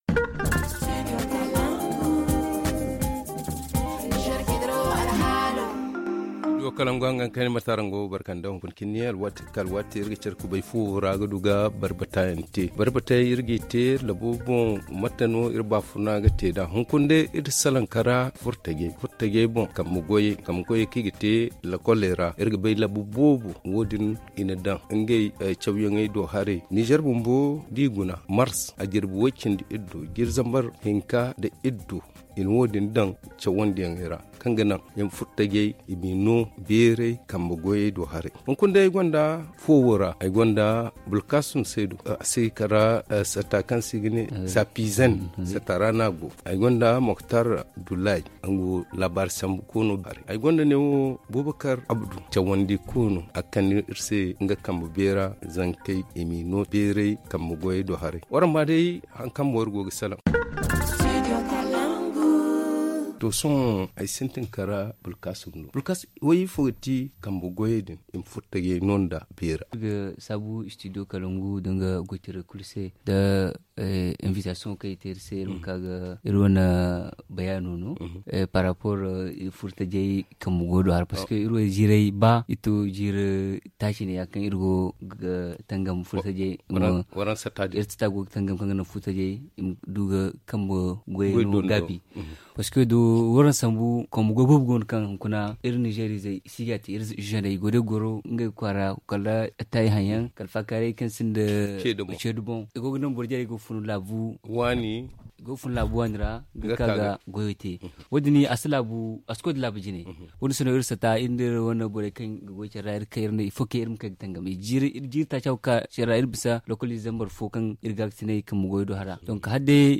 Le forum en zarma